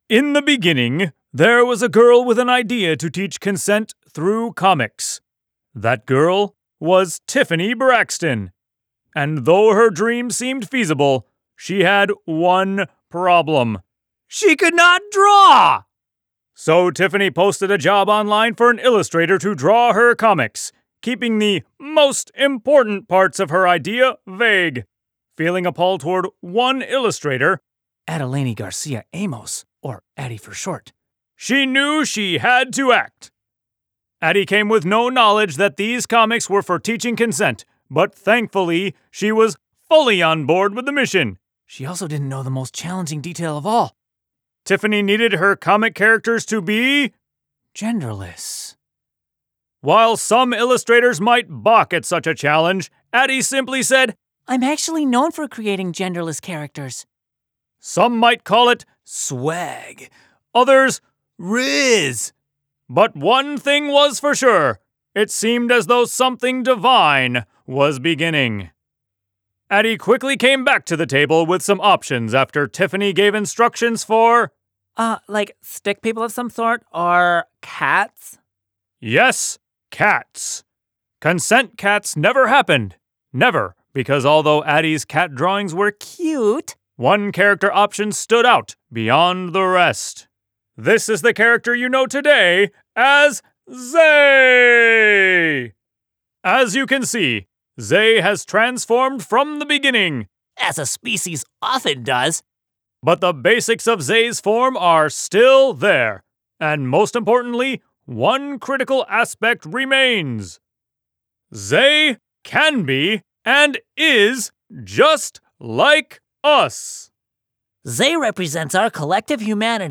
(Read it in your best overly dramatic superhero voice, or listen to the recording)